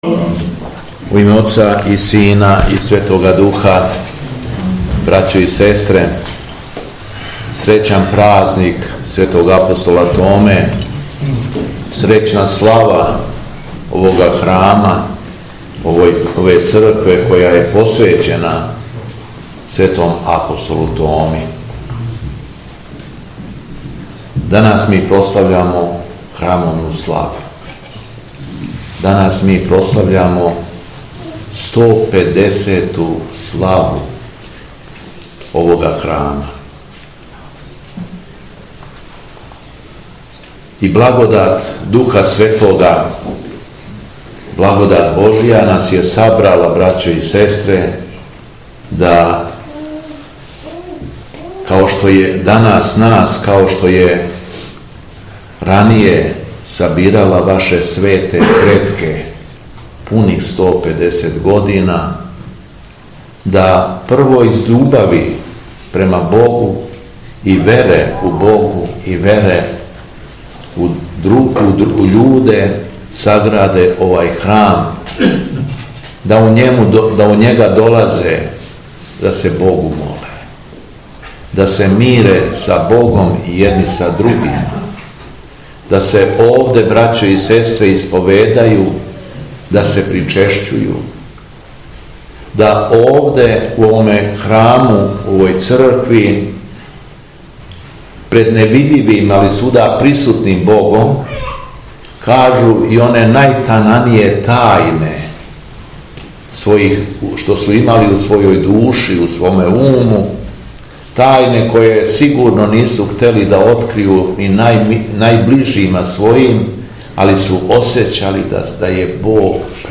Беседа Његовог Преосвештенства Епископа шумадијског г. Јована у Јеловику
Епископ је верницима честитао храмовну славу а потом их поучио својом беседом: